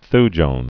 (thjōn, thy-)